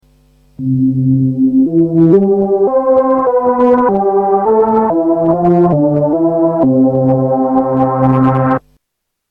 Tags: Sound Effects Orca Demos FXpansion Orca FXpansion Soft Synth